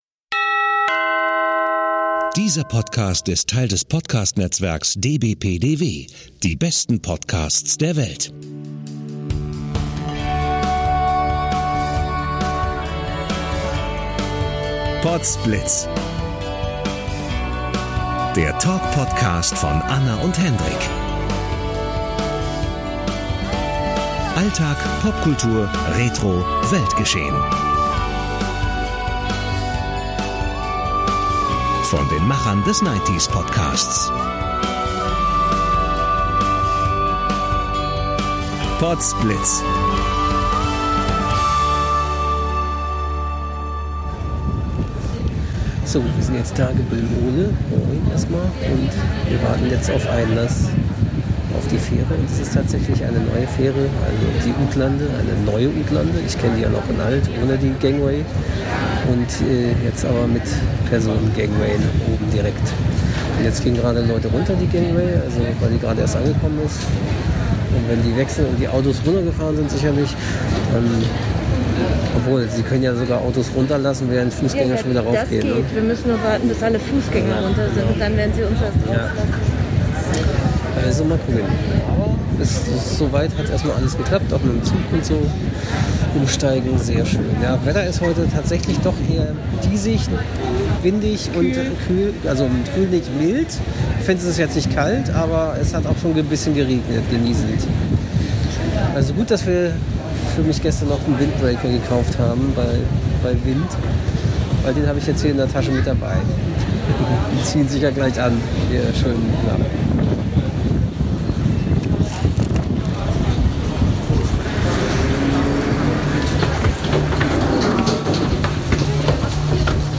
- Und ihr seid wie immer quasi fast live dabei in diesem PLOG, einem Reisetagebuch in Podcast-Form.